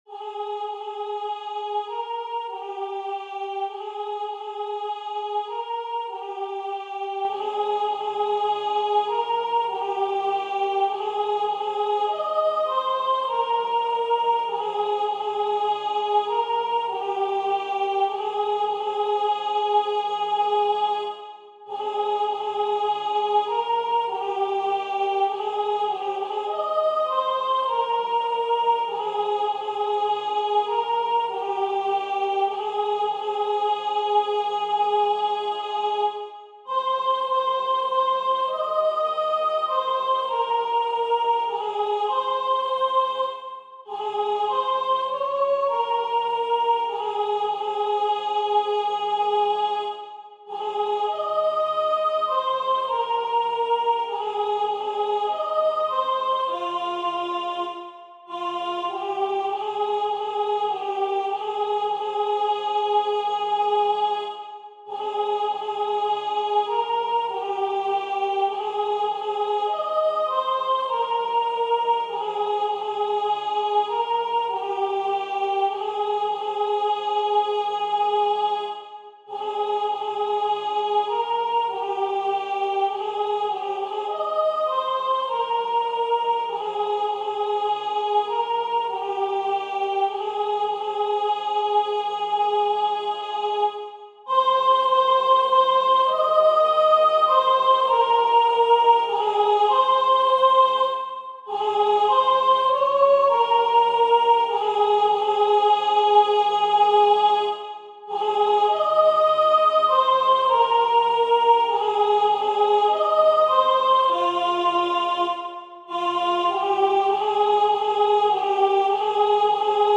- Œuvre pour chœur à 4 voix mixtes (SATB) + 1 voix soliste
Soprano Voix Synth